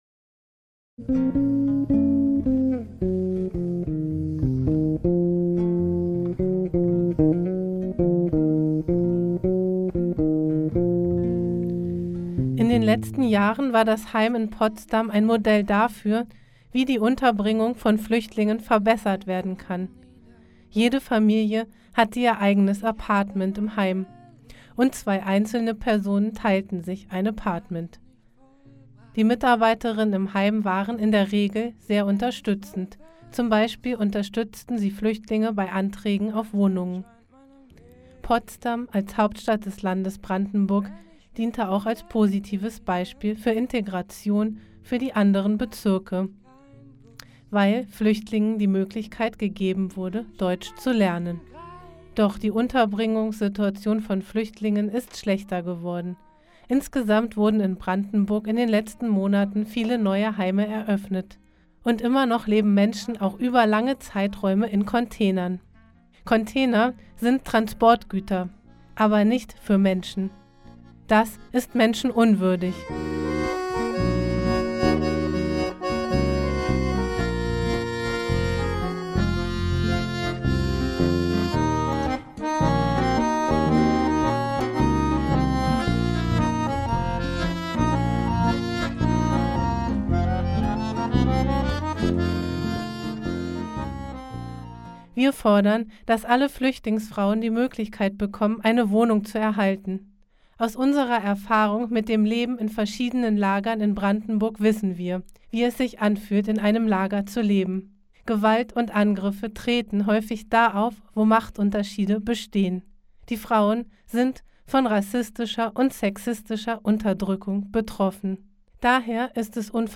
Demo am 13.06.2015
Der Redebeitrag von Women in Exile zum Nachhören